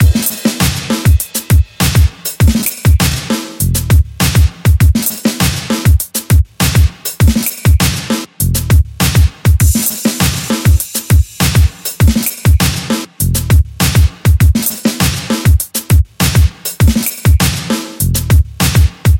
标签： 100 bpm Rap Loops Groove Loops 3.23 MB wav Key : Unknown
声道立体声